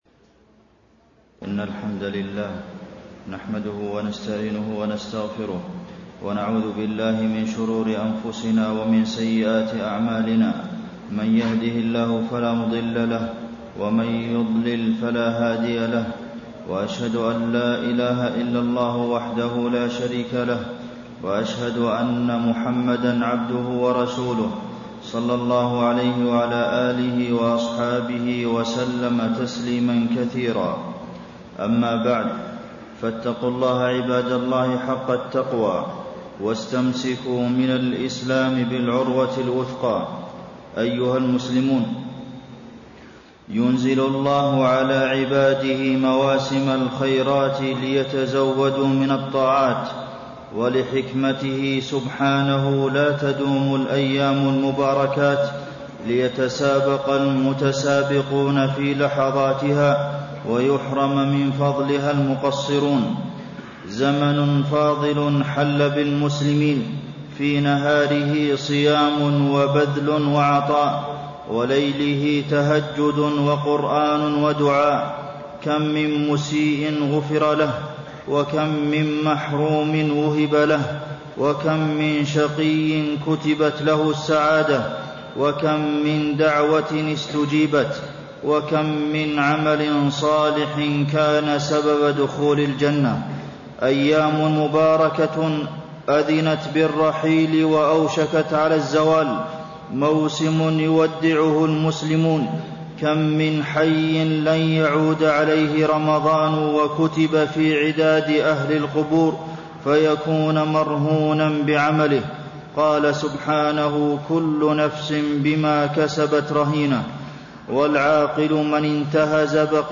تاريخ النشر ٢٩ رمضان ١٤٣٣ هـ المكان: المسجد النبوي الشيخ: فضيلة الشيخ د. عبدالمحسن بن محمد القاسم فضيلة الشيخ د. عبدالمحسن بن محمد القاسم الثبات على الطاعات بعد شهر الرحمات